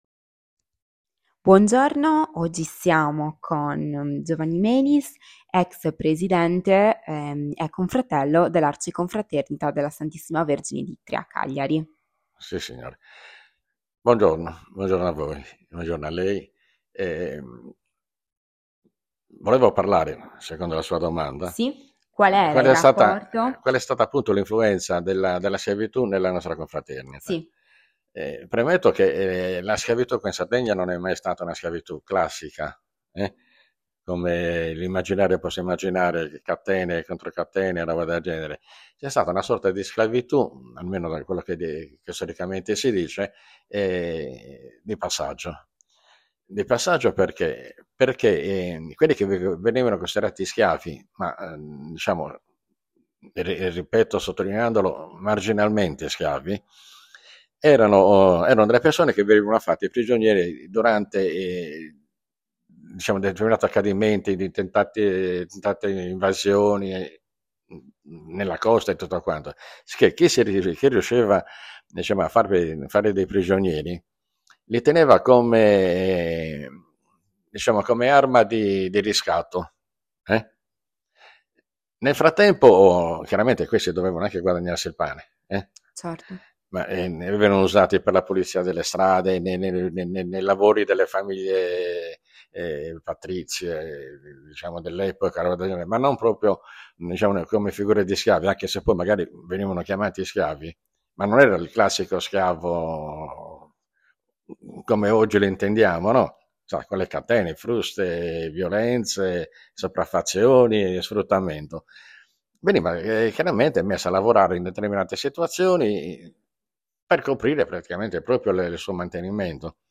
Intervista
Luogo dell'intervista Cagliari
Apparecchiatura di registrazione Microfono e cellulare